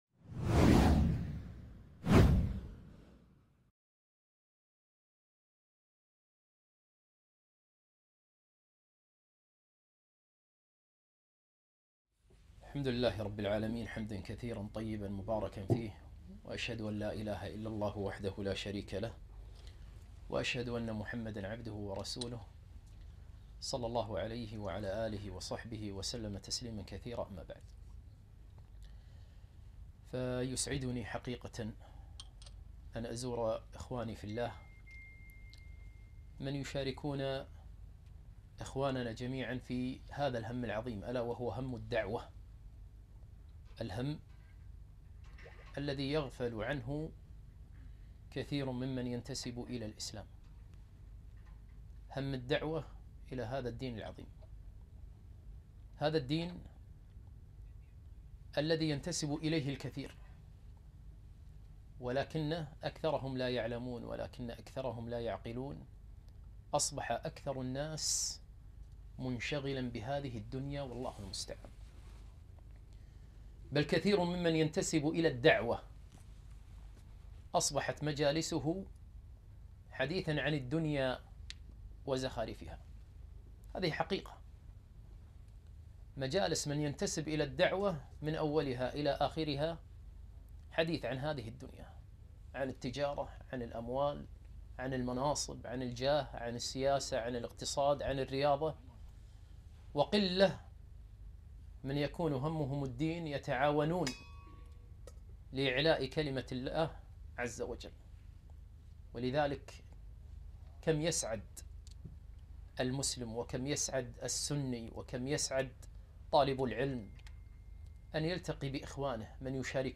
محاضرة - من أخلاق النبي صلى الله عليه و سلم